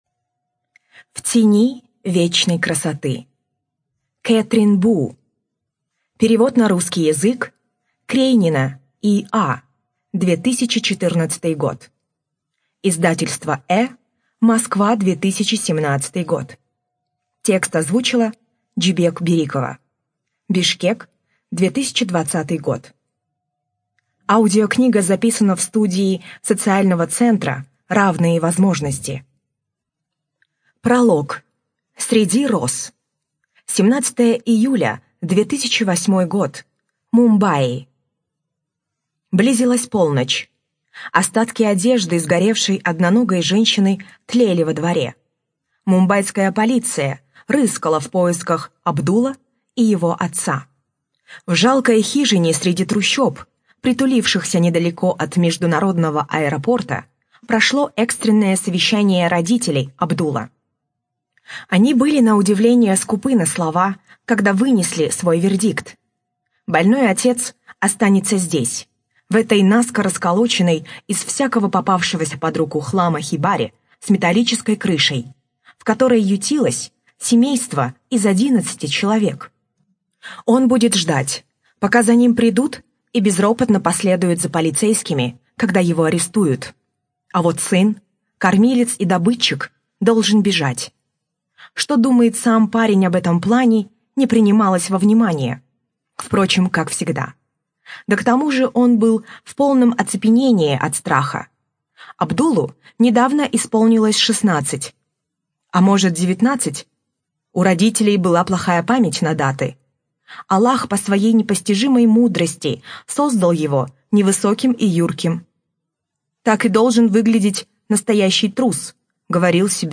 Студия звукозаписиСоциальный центр "Равные возможности" (Бишкек)